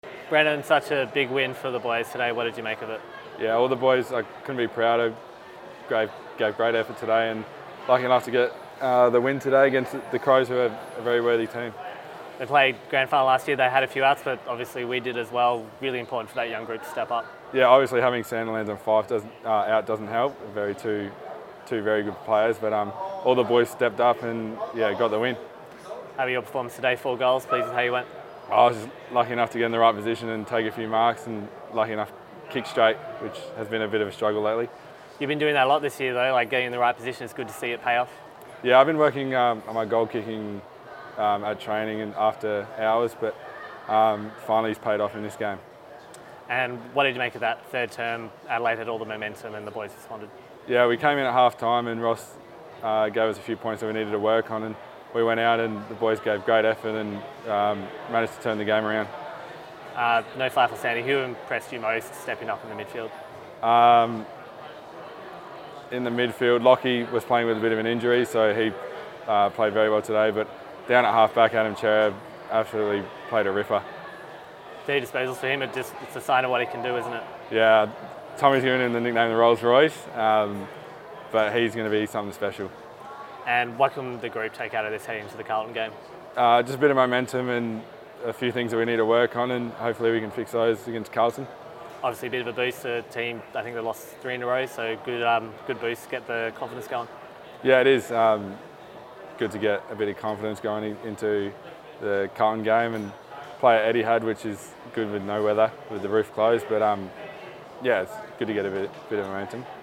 Brennan Cox post-match interview - Rd 12 v Adelaide